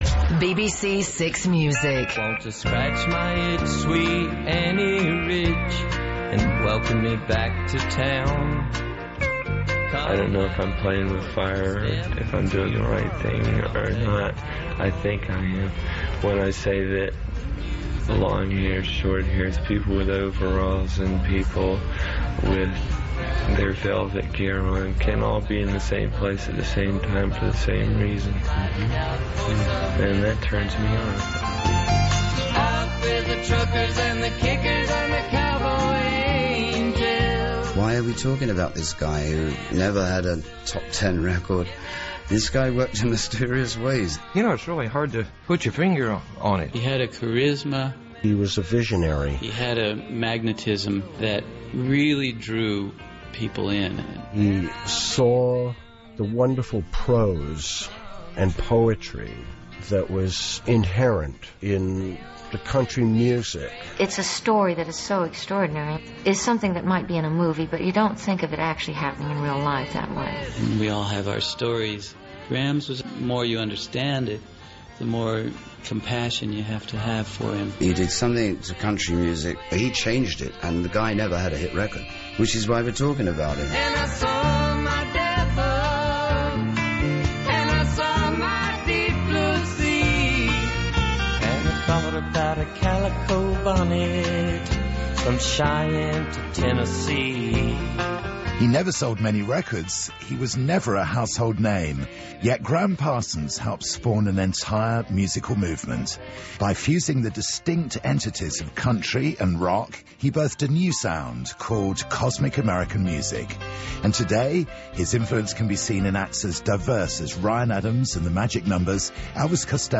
Part 1 of "Grevious Angel" Radio Documentary
This is the first part of a BBC Radio documentary about the life of Gram Parsons.